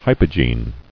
[hy·po·gene]